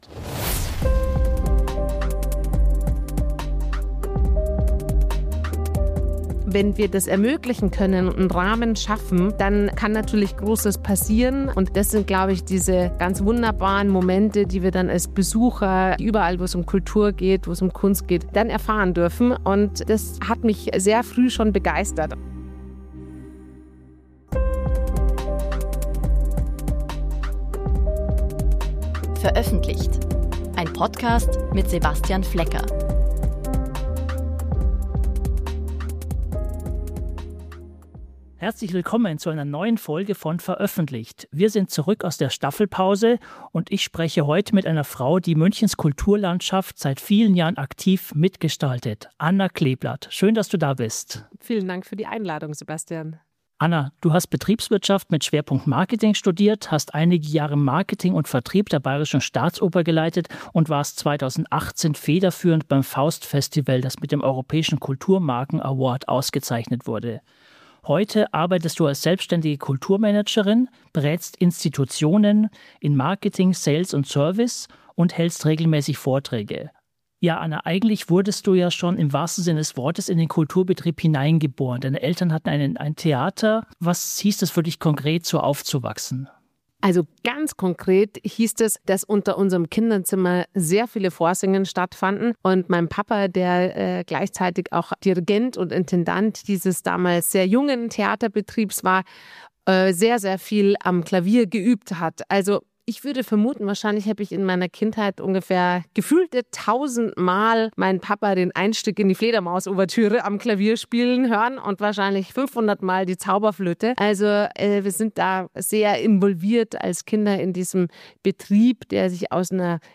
Ein Gespräch über den Weg vom Theaterkind zur Kulturmanagerin – und darüber, wie partizipative Formate wie das Bachfest München entstehen.